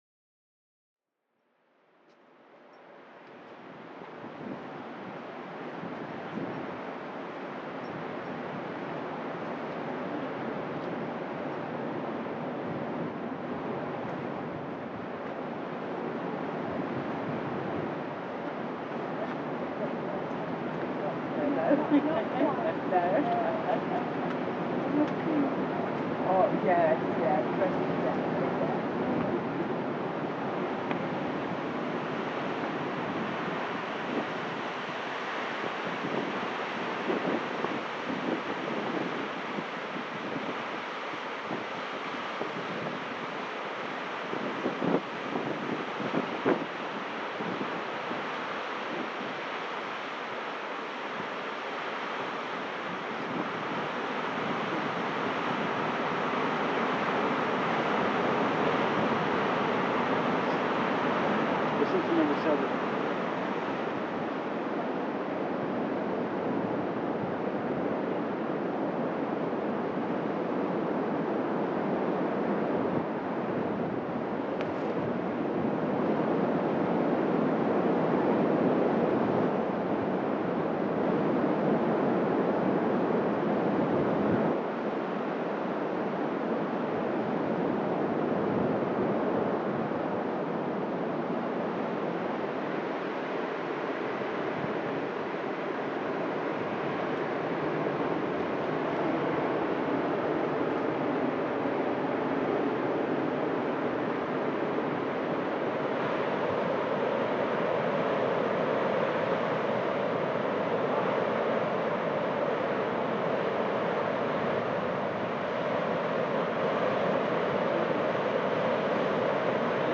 The day was very cold, wet and blustery as we walked around the area. In the recording, you hear a lot of noise from the wind and the water pounding the shore but sometimes you can hear different voices of people as they pass us by.
——————— This sound is part of the Sonic Heritage project, exploring the sounds of the world’s most famous sights.